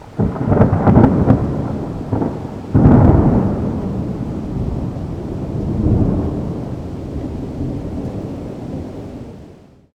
Thunder_3.ogg